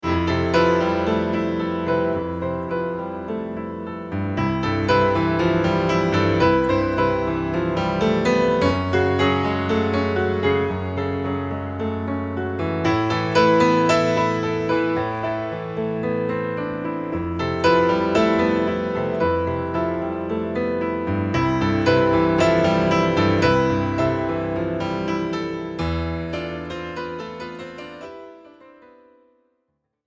percussion
drums